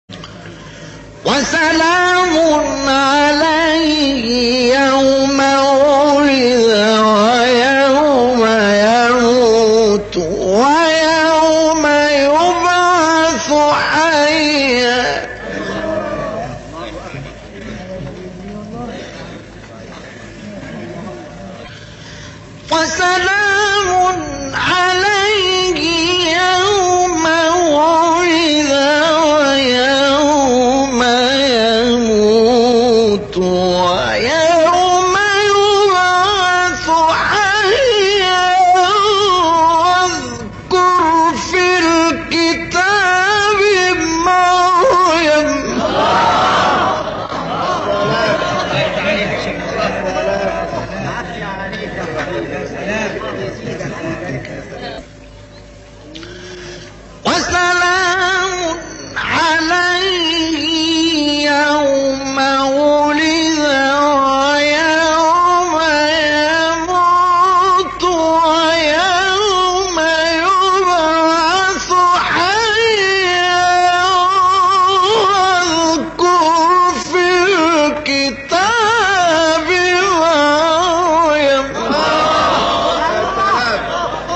گروه شبکه اجتماعی: مقاطع صوتی از تلاوت قاریان بنام و برجسته جهان اسلام که در شبکه‌های اجتماعی منتشر شده است، می‌شنوید.